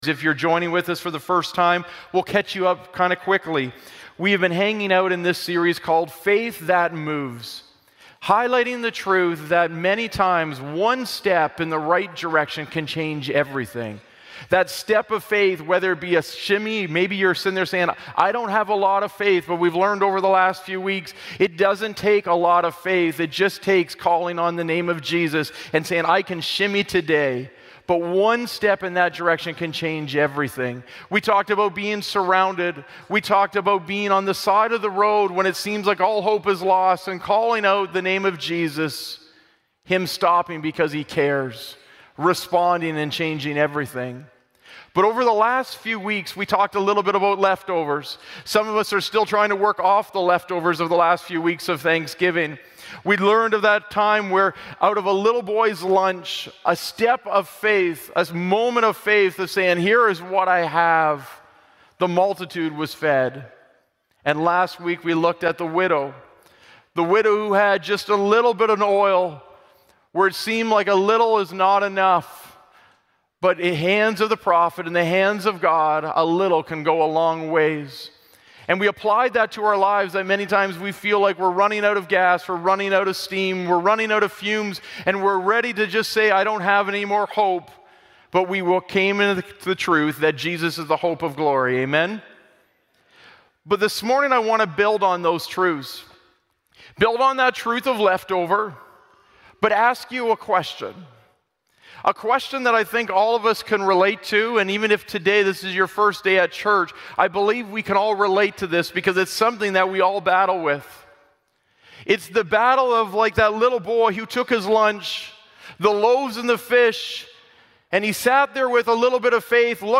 Glad Tidings Church (Sudbury) - Sermon Podcast